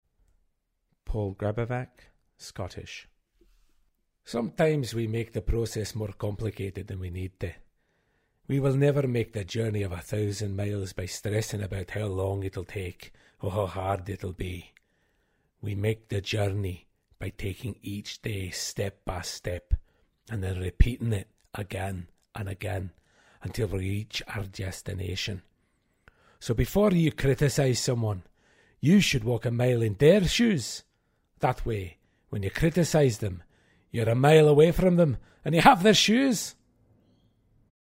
Male, 40s
Glaswegian